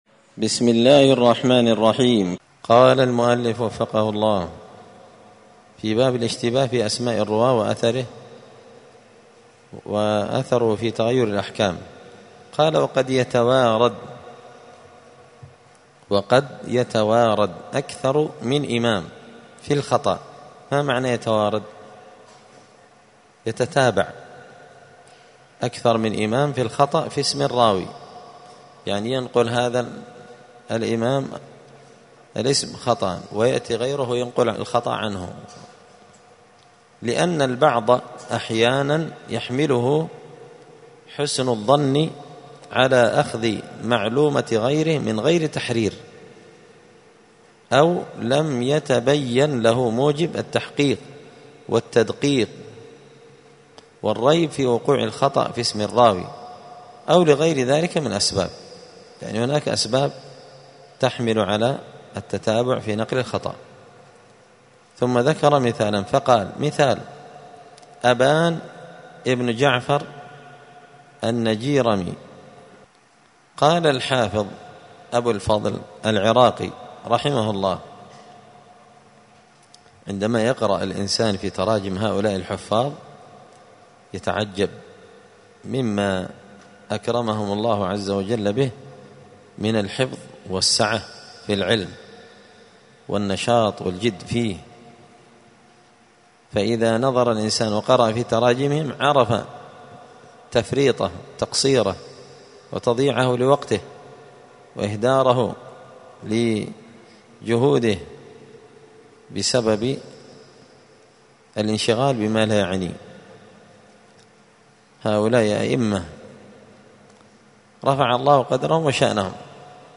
*الدرس الثاني والثلاثون (32) تابع لباب الاشتباه في أسماء الرواه وأثره في تغير الأحكام*
دار الحديث السلفية بمسجد الفرقان بقشن المهرة اليمن